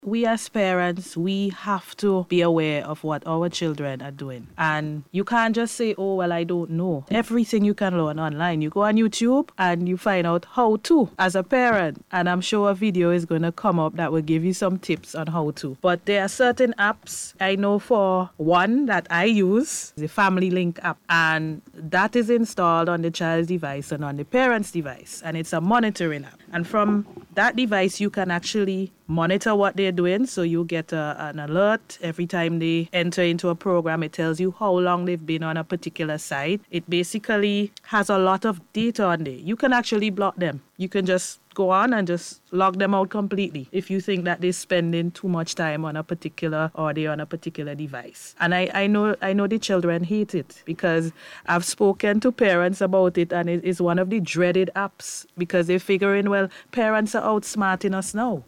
issued the advice on Monday night during the On the Beat Program on NBC Radio.